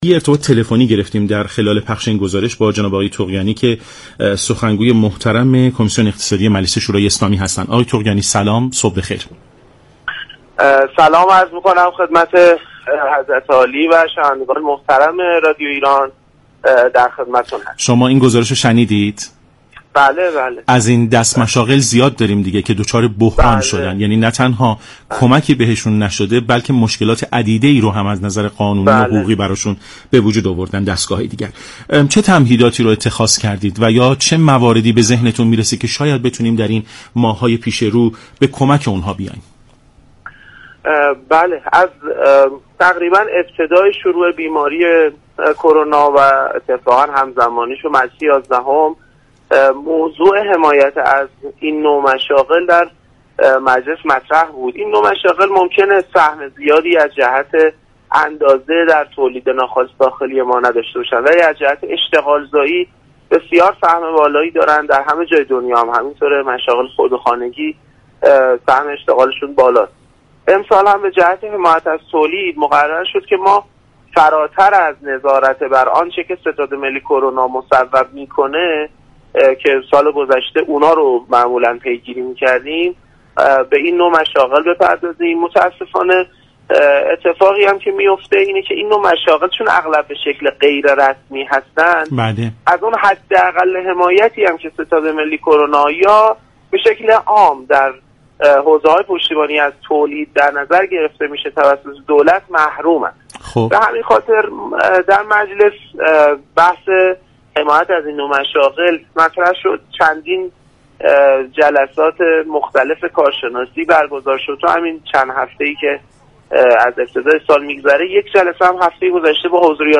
به گزارش شبكه رادیویی ایران، مهدی طغیانی سخنگوی كمیسیون اقتصادی مجلس در برنامه سلام صبح بخیر رادیو ایران در پاسخ به این پرسش كه مجلس برای حل مشكلات مشاغل خرد چه تمهیداتی اندیشیده است؟